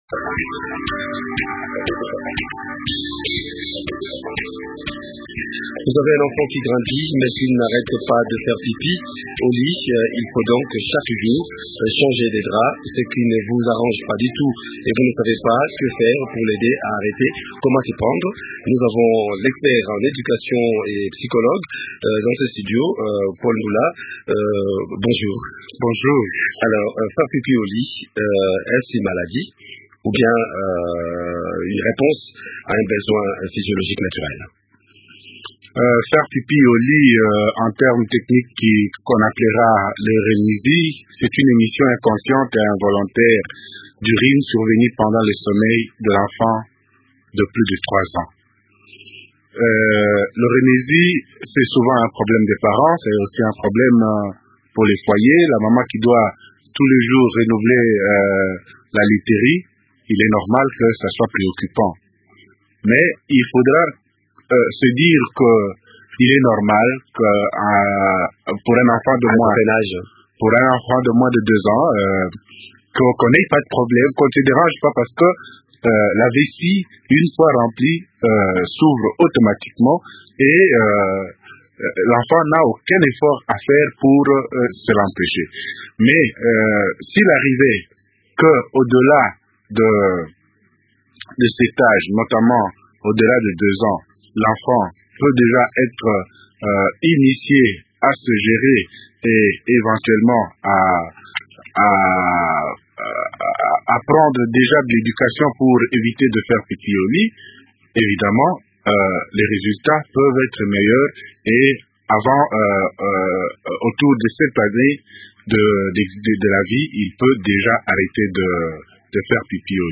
Le psychologue et éducateur à la vie